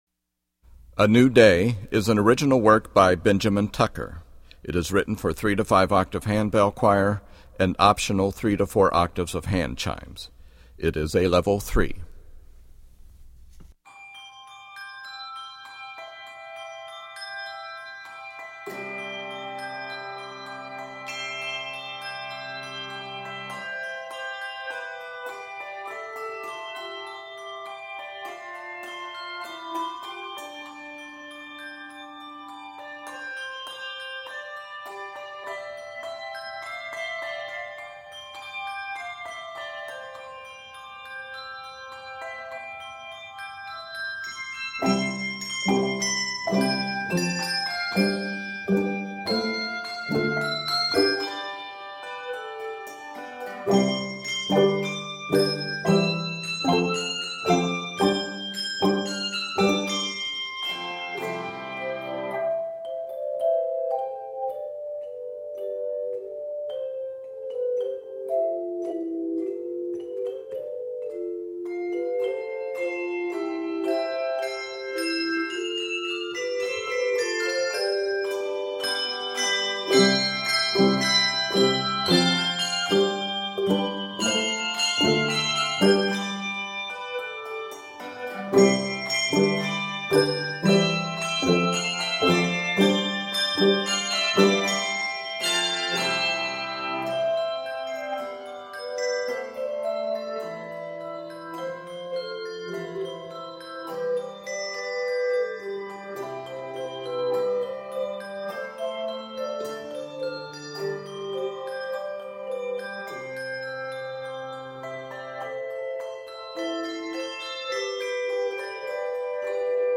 is composed in C Major and A Major.